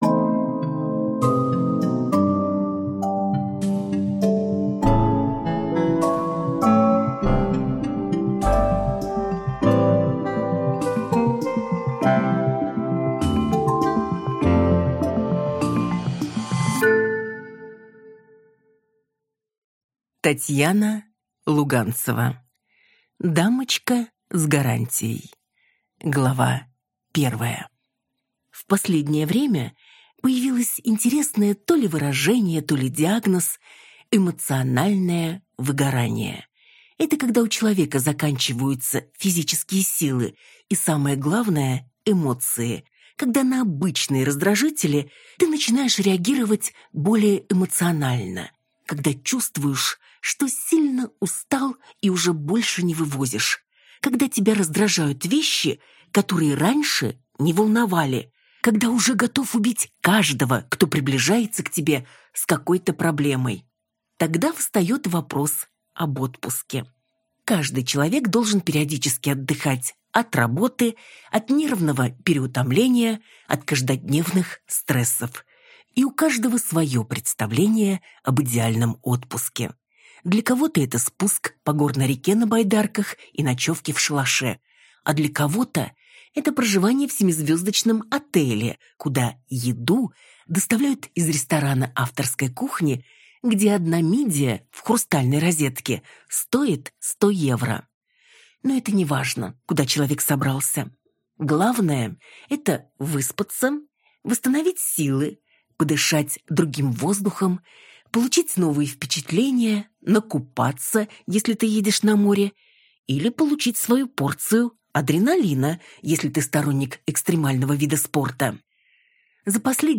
Аудиокнига Дамочка с гарантией | Библиотека аудиокниг